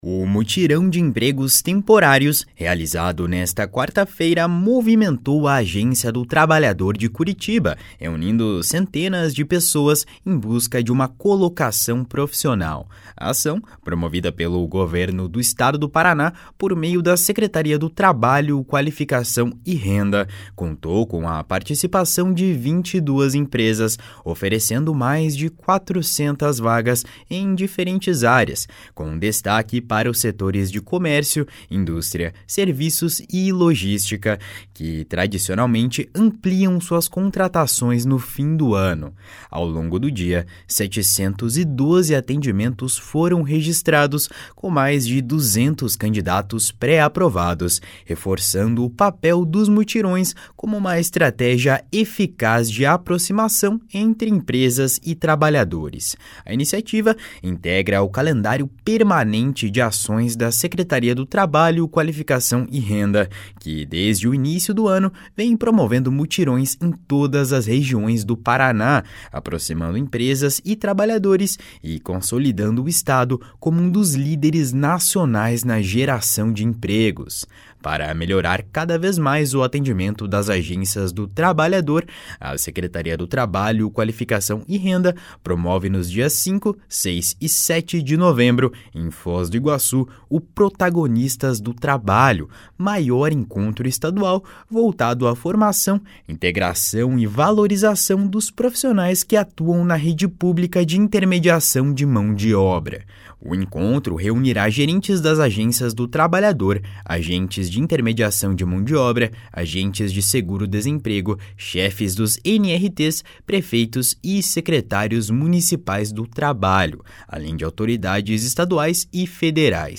O Mutirão de Empregos Temporários, realizado nesta quarta-feira, movimentou a Agência do Trabalhador de Curitiba, reunindo centenas de pessoas em busca de uma colocação profissional.